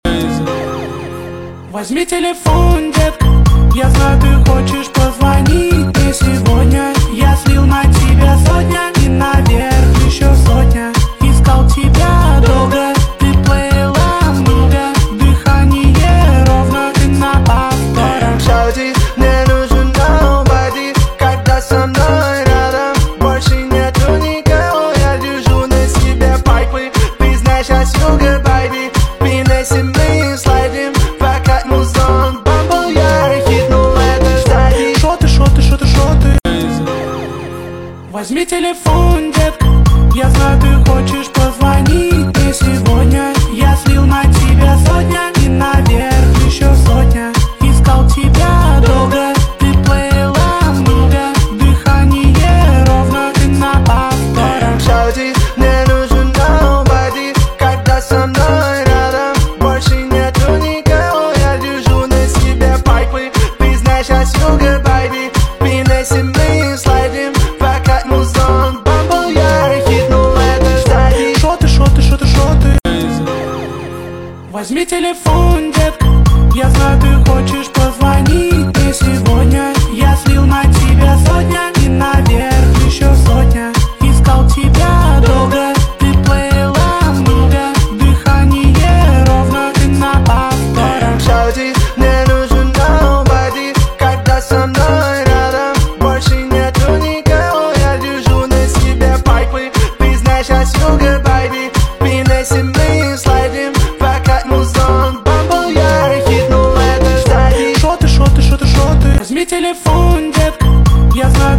stereo Рэп